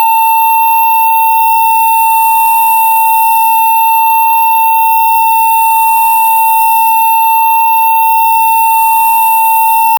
*Final*: Um tom ascendente digital, transmitindo sucesso ou confirmação. 0:10 How can I download it 0:10 A star trek style computer confirmation beep 0:10
a-star-trek-style-compute-o2uuy53l.wav